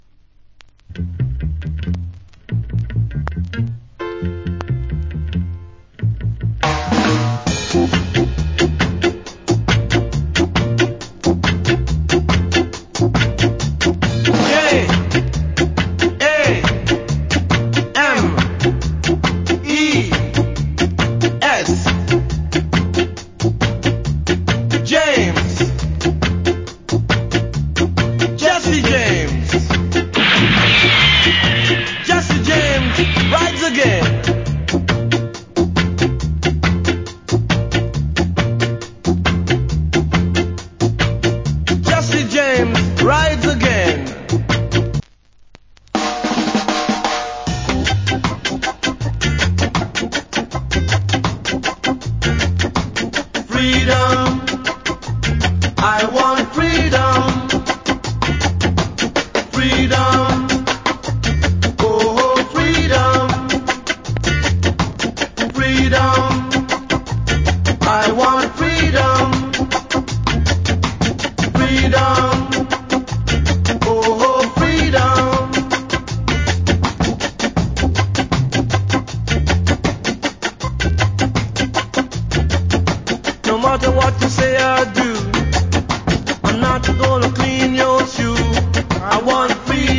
Good Skinhead Reggae Vocal.